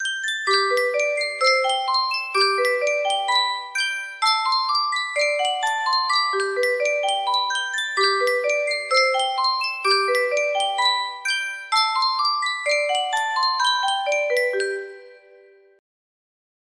Sankyo Music Box - Home Sweet Home BX music box melody
Full range 60